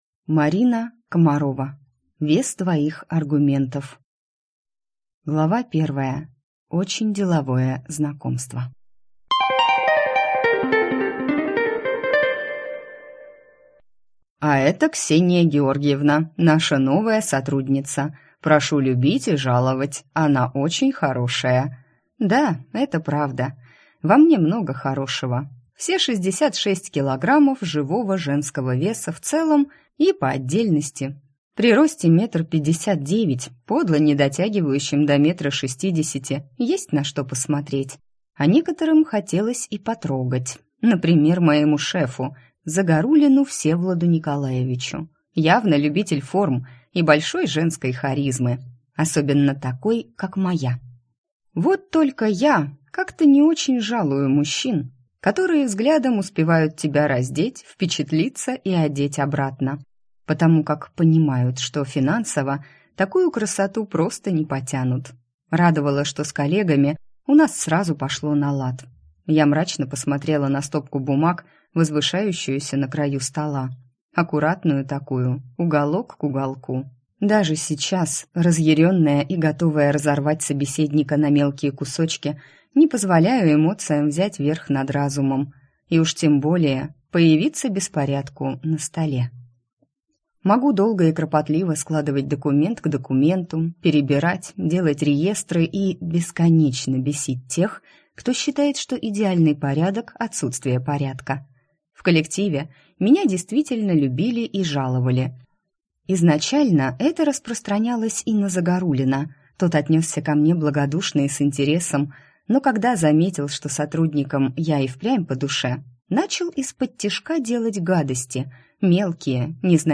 Аудиокнига Вес твоих аргументов | Библиотека аудиокниг